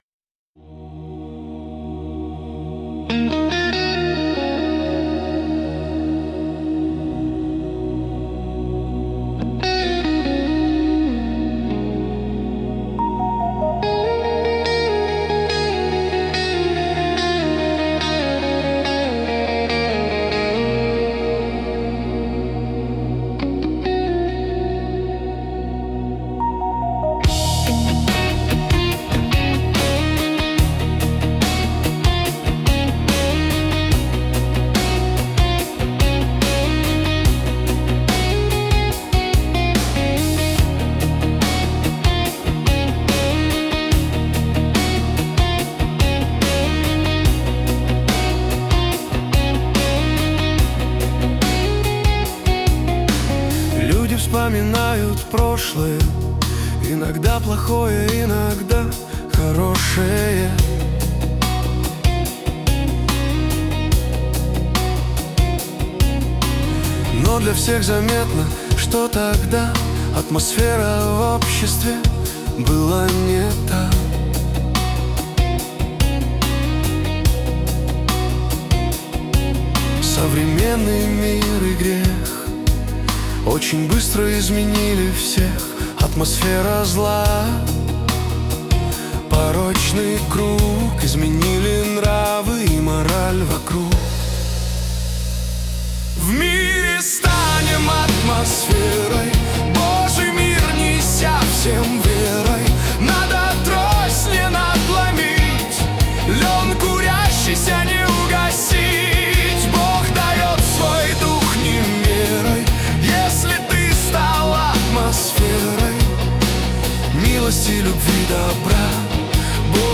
песня ai
166 просмотров 1018 прослушиваний 70 скачиваний BPM: 72